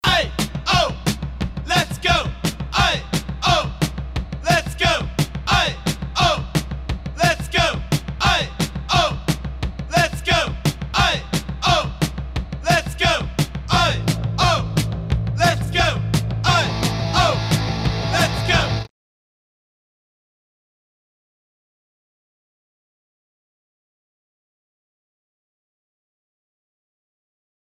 Tags: Princeton Hockey Baker Rink